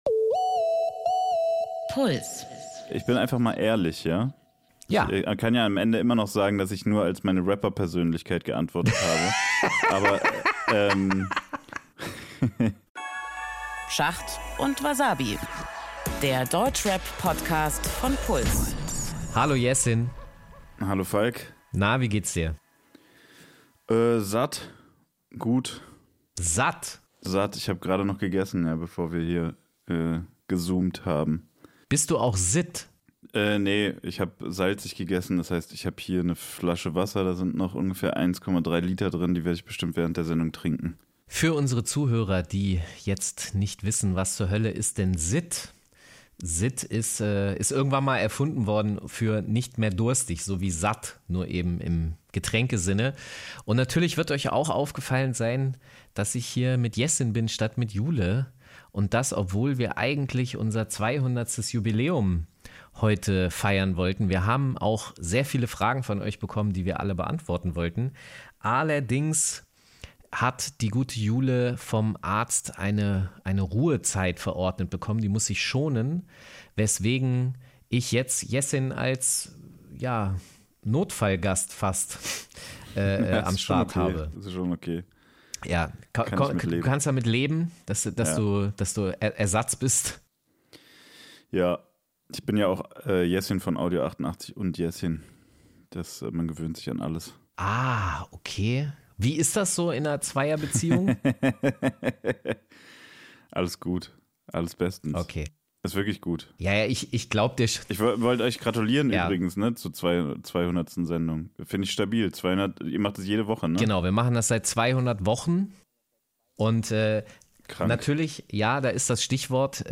Die beiden HipHop-Journalisten bohren dicke Bretter, diskutieren Nichtigkeiten und geben einen Blick hinter die Kulissen des Rap-Zirkus in Deutschland.